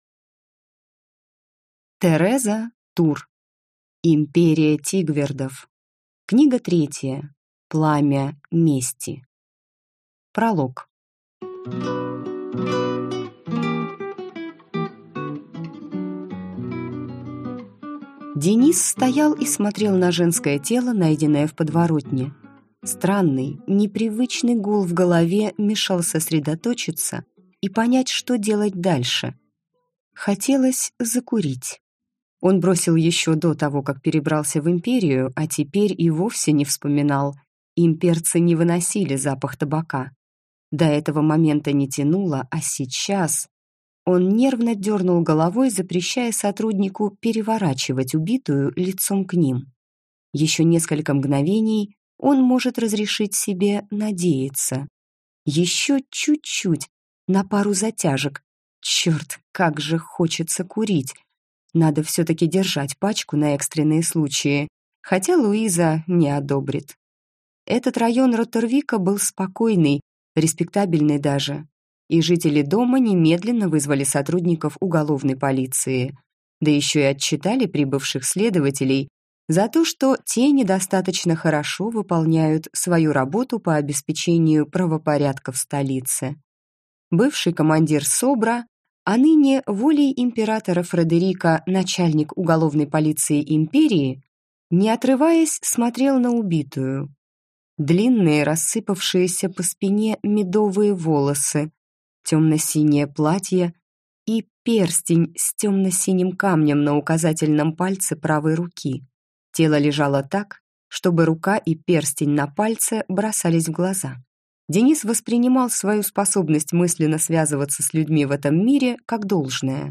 Аудиокнига Империя Тигвердов. Пламя мести | Библиотека аудиокниг